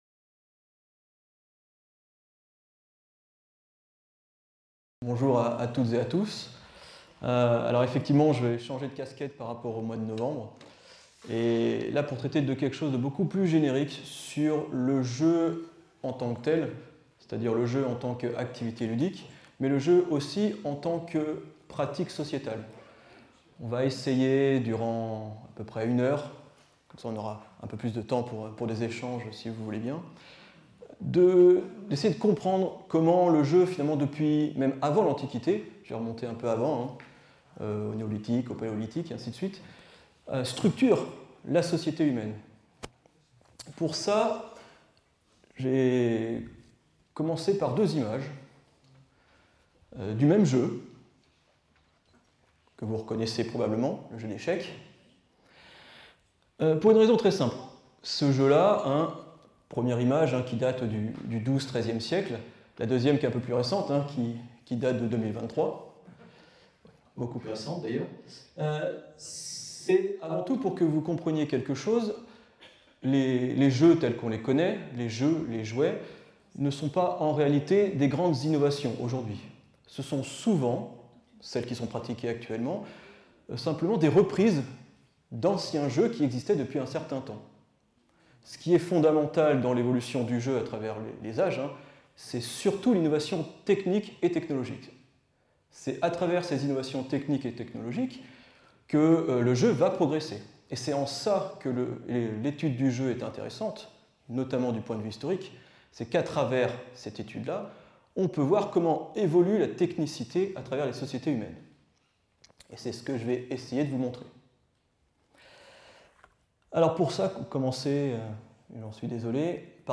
Cette conférence se propose de traiter de l’histoire du jeu depuis l’Antiquité. Le propos cumulera deux aspects : l’évolution de l’objet ludique et pédagogique, des chars miniatures du Néolithique aux jeux vidéo, et les fonctions de l’activité de jeu depuis l’Antiquité, de la notion de jeu sacré comme les Panathénées à la régulation du jeu voire son interdiction au XIVe siècle par exemple.